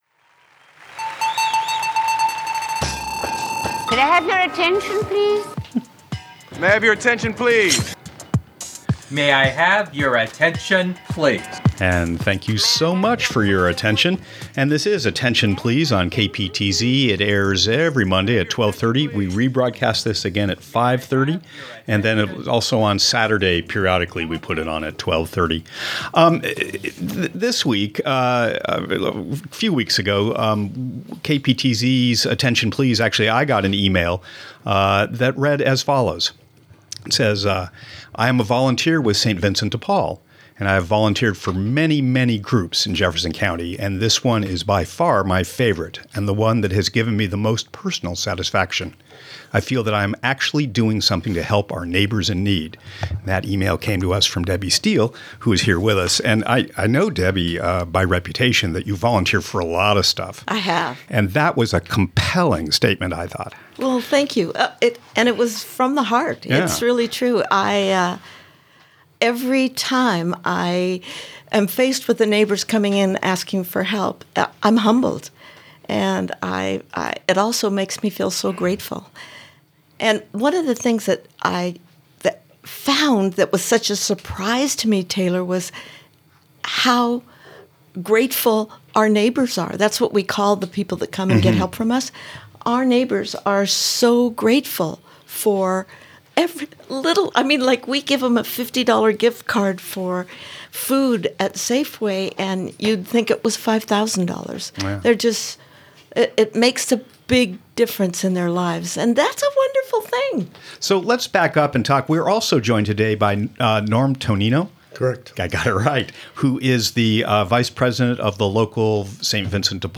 KPTZ interview, January 12, 2026.